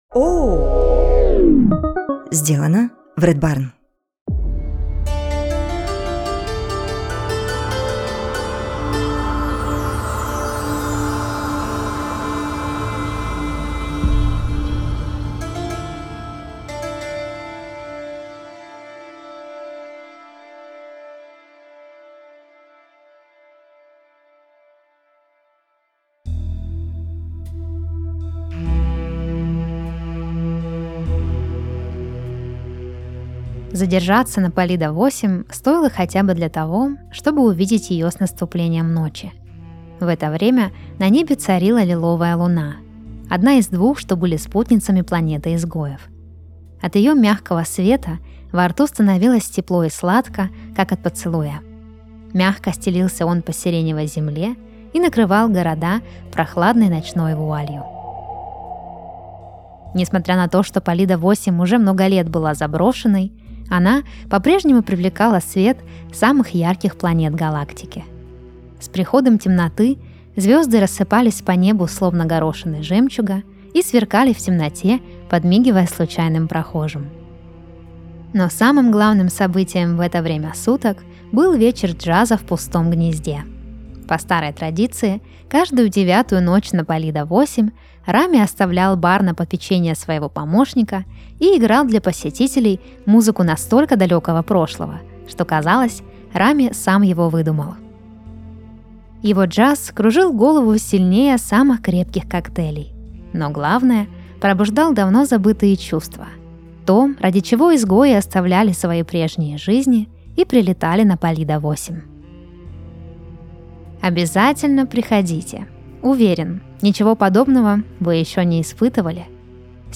Каждый выпуск слушатели присылают нам свои сны, а мы переделываем их в художественный рассказ и зачитываем под расслабляющую музыку.